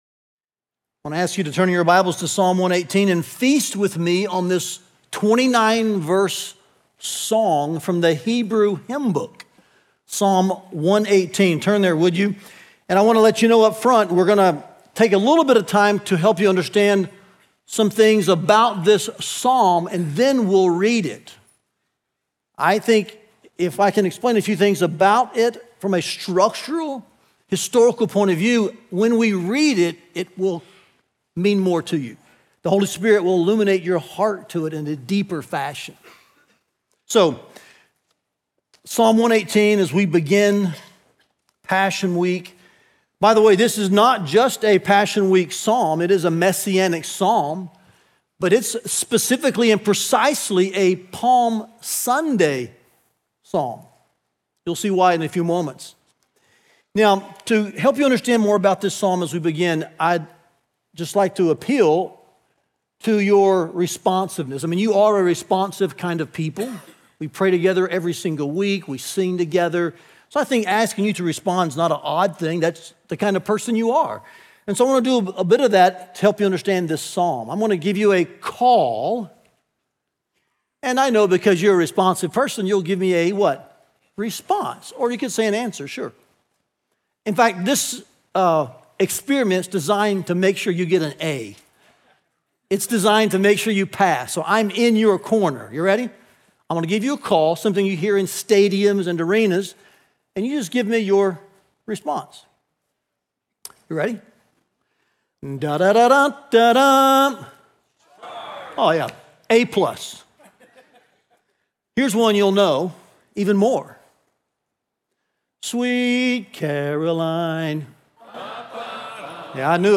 Listen to the first sermon of our Passion Week series “The Way of the Messiah.”